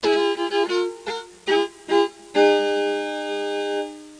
fiddle.mp3